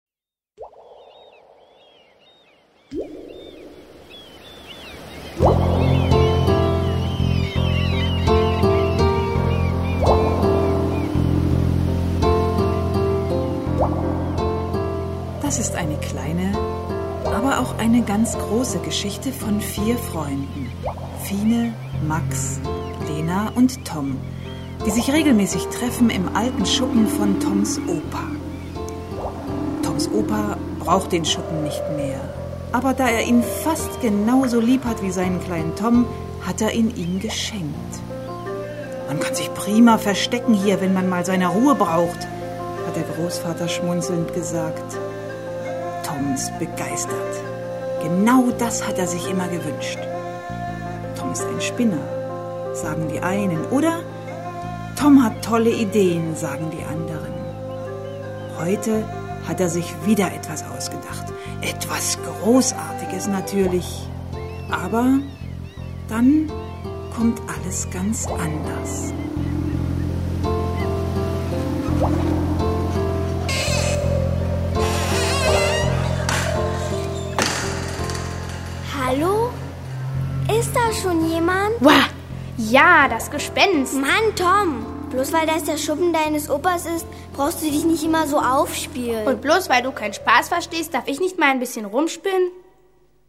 Hörspiel für Kinder/Jugendliche